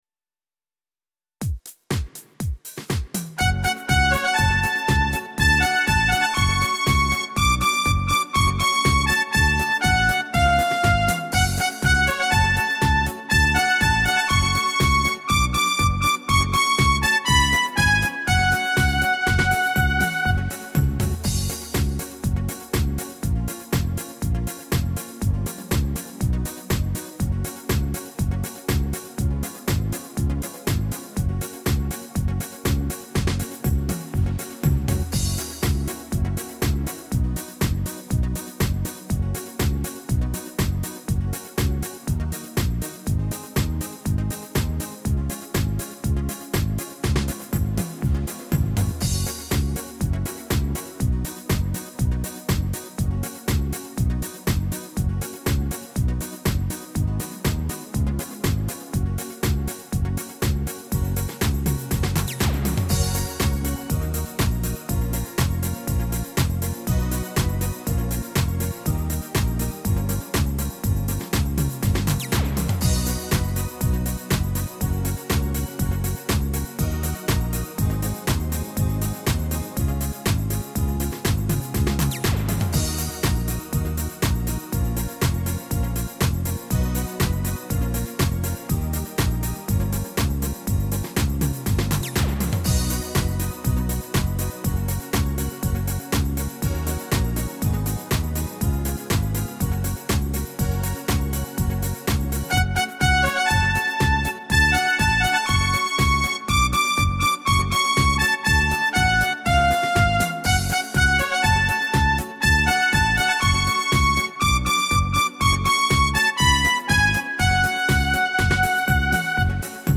Tone Nam (F#)
•   Beat  01.